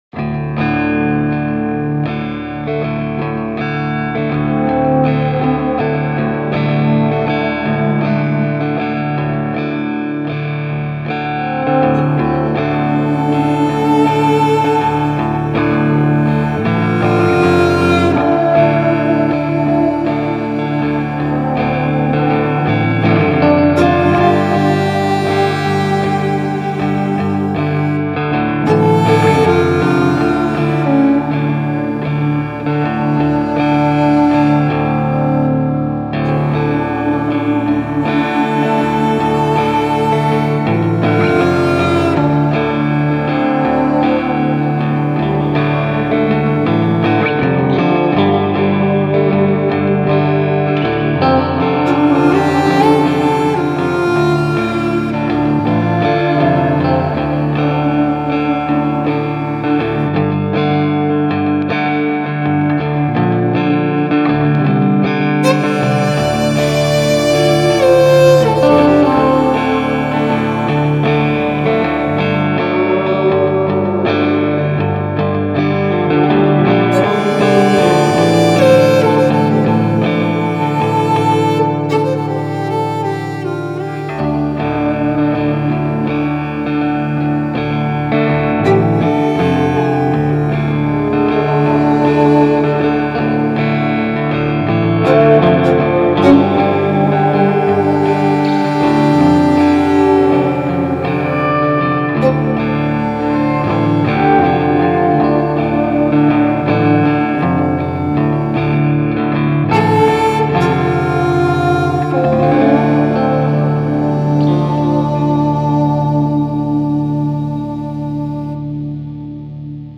Country Tracks, Ethnic and World